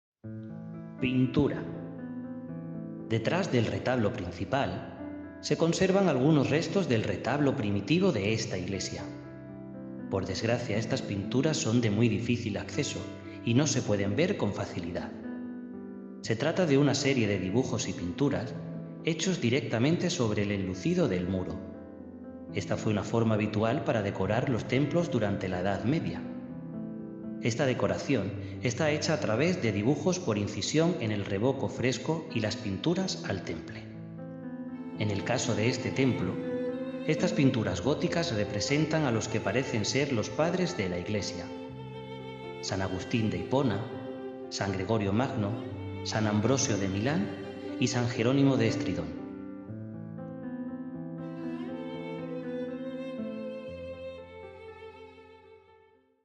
Retablo principal / Chancel-Main Altarpiece » Pintura / Painting Para mejor uso y disfrute colóquese los auriculares y prueba esta experiencia de sonido envolvente con tecnología 8D. For better use and enjoyment, put on the headphones and try this surround sound experience with 8D technology.